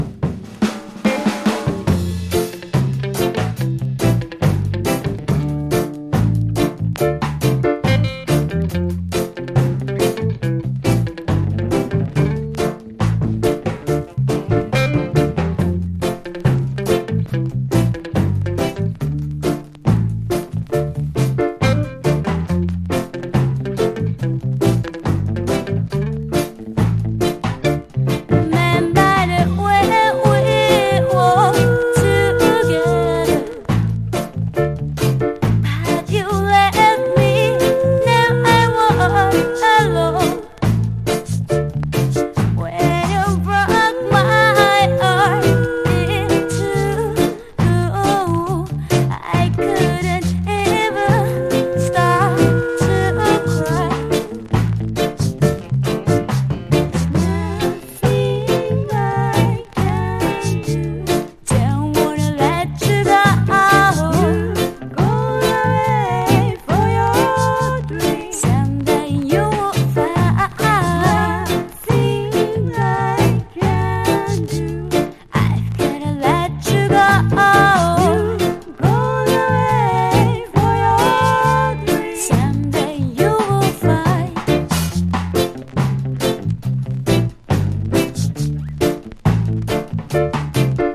奇跡のジャパニーズ・ガールズ・ロックステディ！